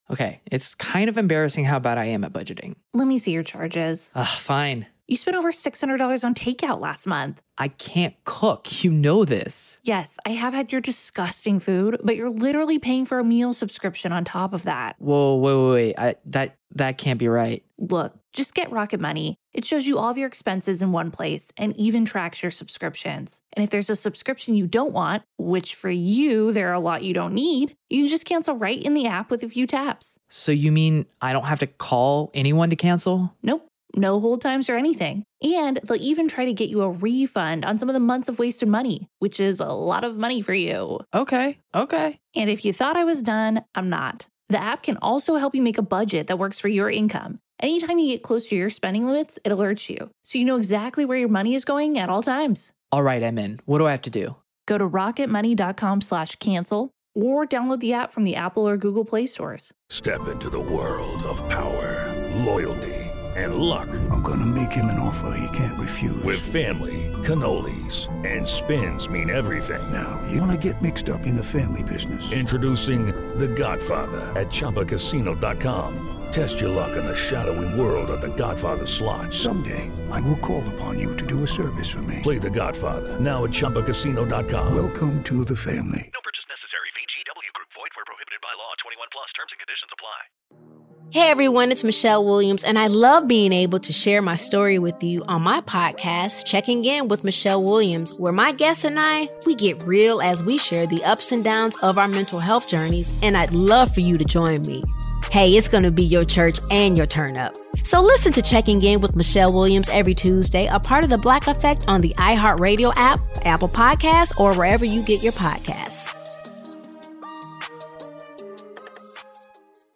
The guys preview their Top 10 fantasy players at the catcher position.